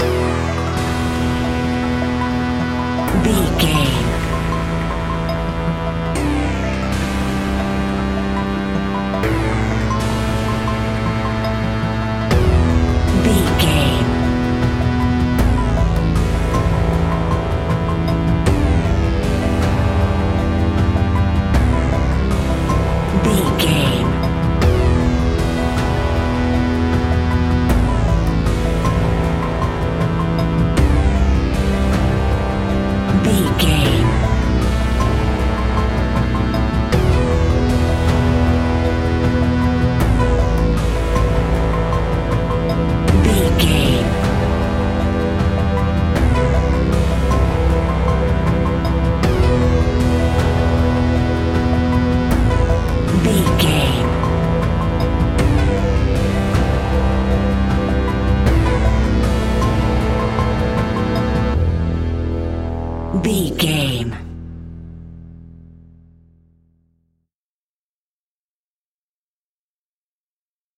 In-crescendo
Thriller
Aeolian/Minor
ominous
dark
haunting
eerie
synthesizer
electronic music
electronic instrumentals
Horror Synths